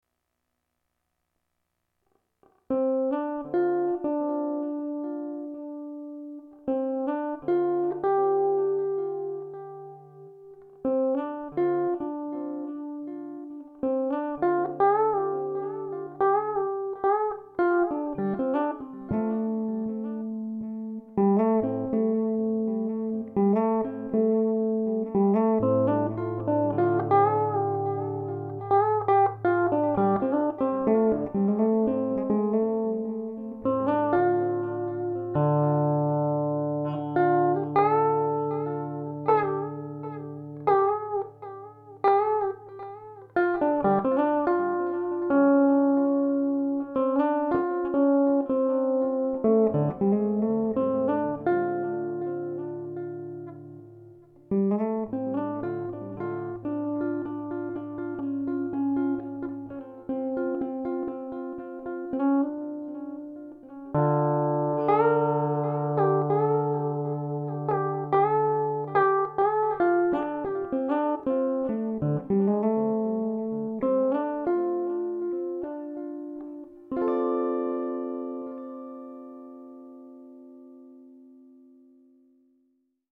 Live electric guitar – Godin LG.